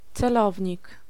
Ääntäminen
IPA: /ʦ̑ɛˈlɔvʲɲik/